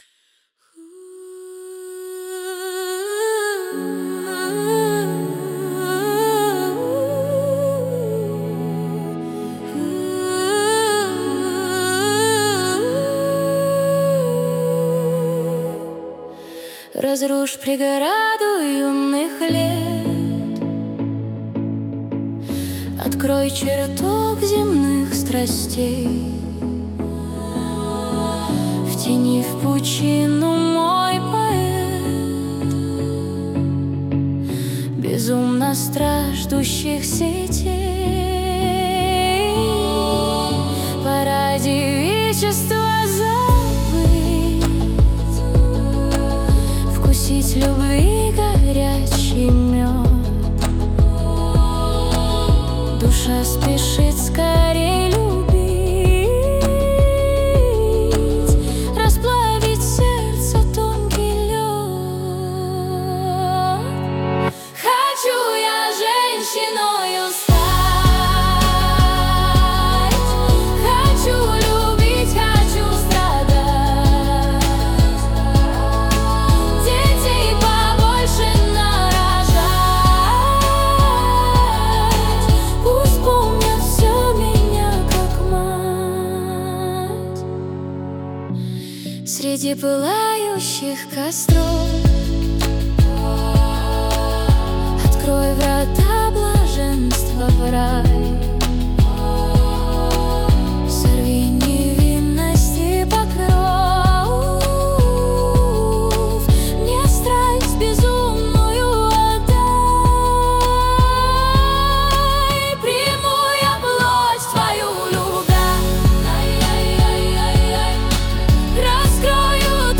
mp3,4287k] AI Generated